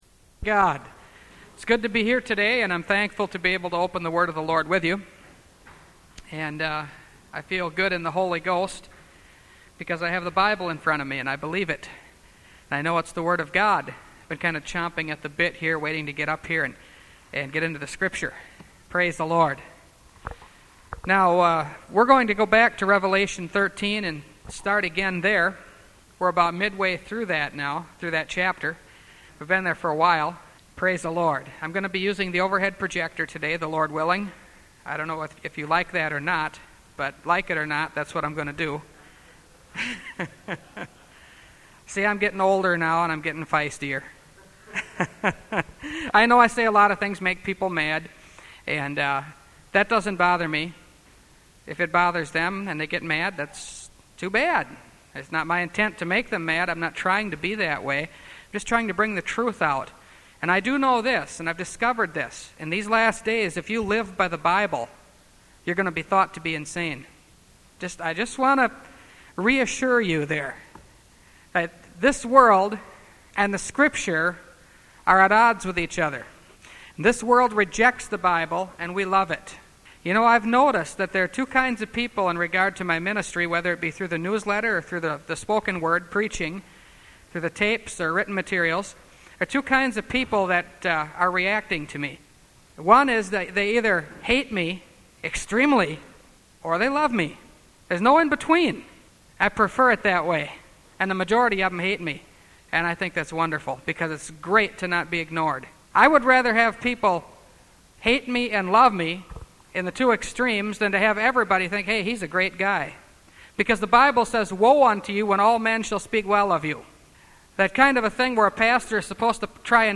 Revelation Series – Part 24 – Last Trumpet Ministries – Truth Tabernacle – Sermon Library